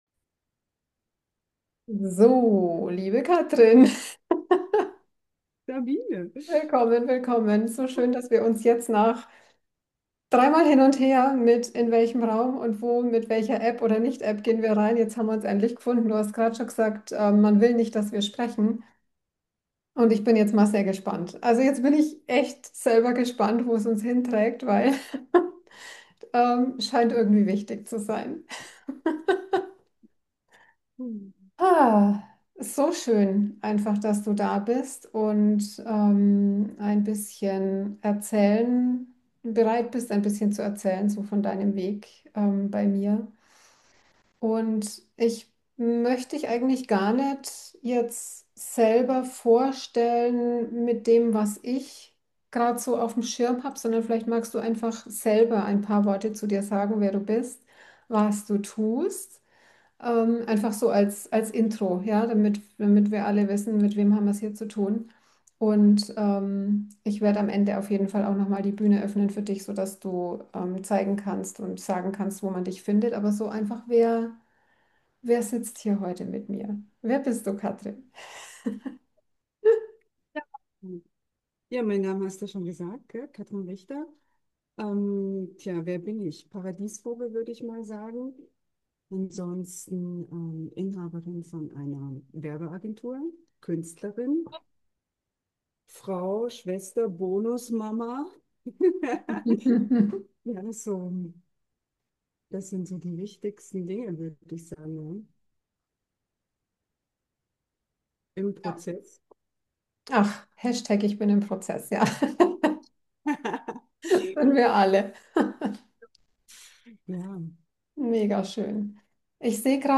Es ist ein Gespräch unter Unternehmerinnen, bei denen NICHT immer alles 100% läuft.